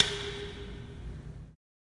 金属瓶" MB指SLL03
描述：用我的金属热水瓶（24盎司）瓶子记录的不同声音制成，用手敲击它。
标签： 金属 声音 命中 铮铮 拟音 平移 节奏 敲击 打击乐器 金属的 处置 冲击 剧痛
声道立体声